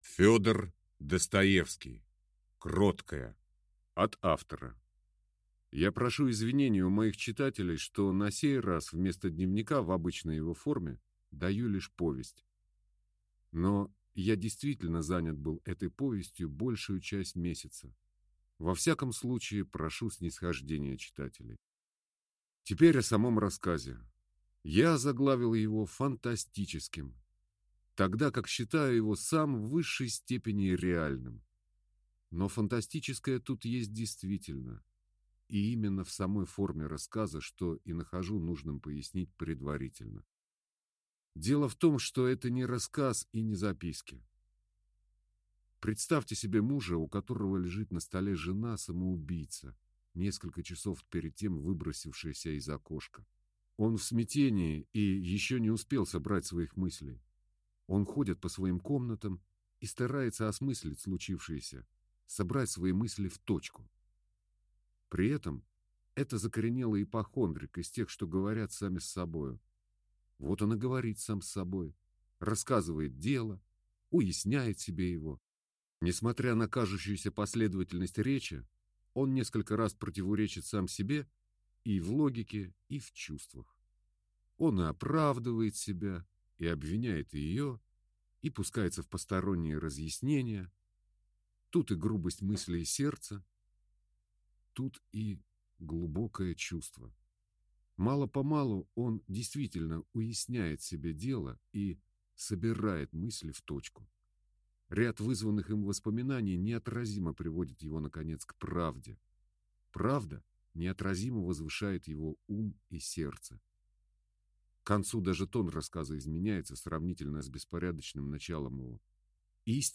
Аудиокнига Кроткая | Библиотека аудиокниг